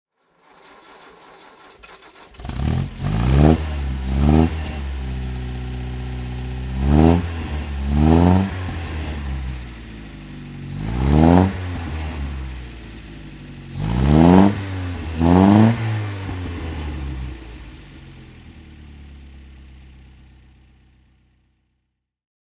Healey Silverstone (1950) - Starten und Leerlauf
Healey_Silverstone_1950.mp3